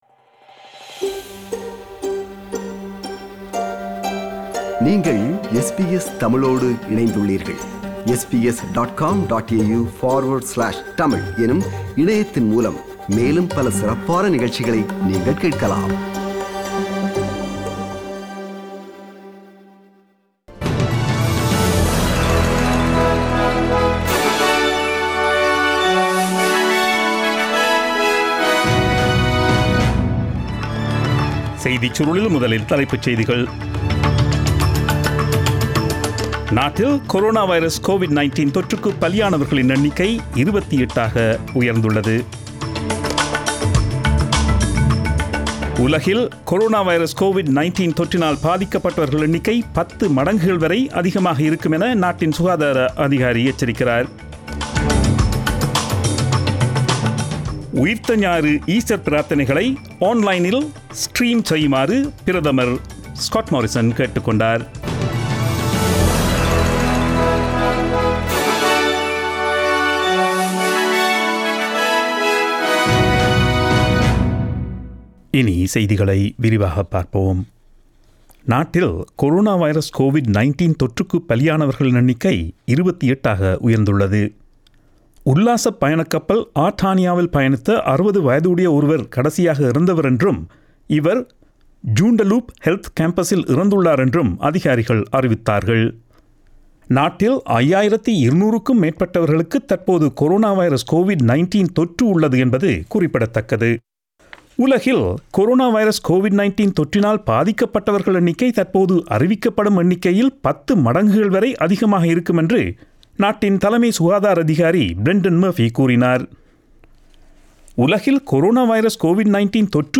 Australian news bulletin aired on Friday 03 April 2020 at 8pm.